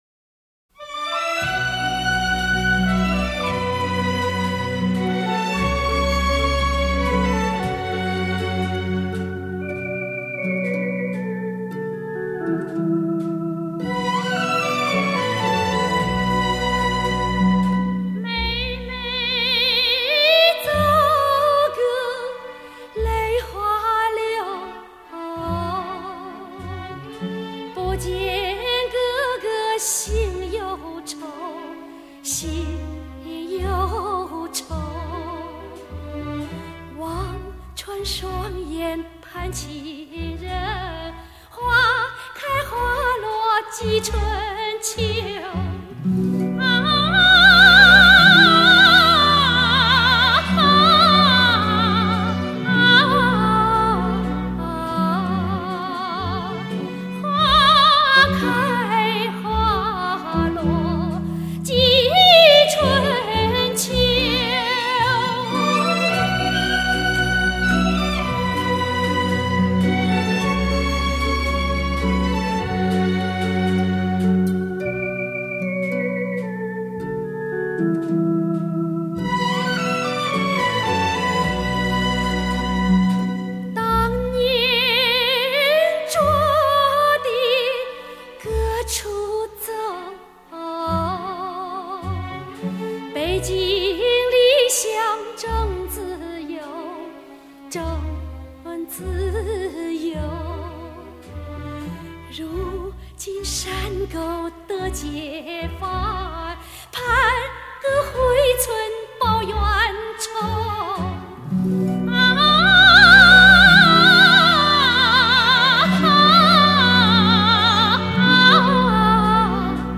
这是一张好唱片，甜美、细腻、通透。
清扬舒缓的伴奏
录音和制作都达到了相当高的水平，听感自然流畅，其中的HI-FI元素应有尽有，中远场录音，场感开阔
声音醇净，如蒸馏水，有点不吃人间烟火的“仙味”！